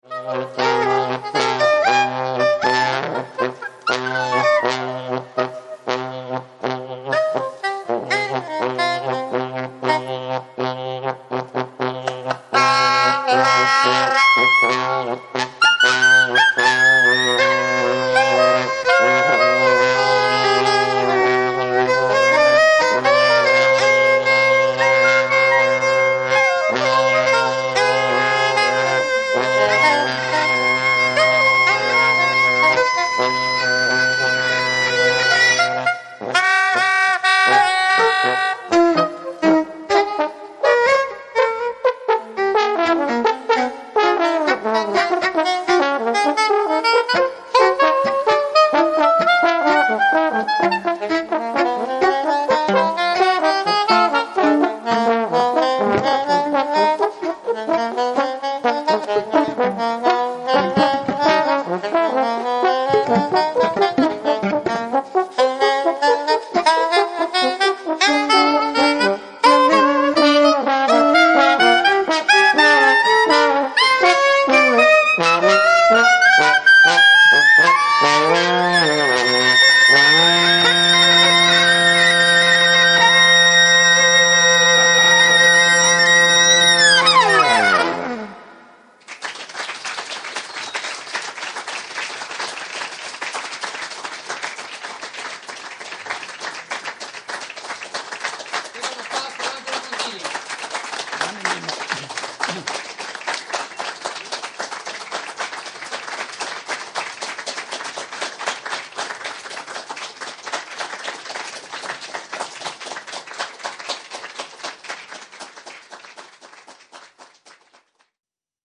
istant compositions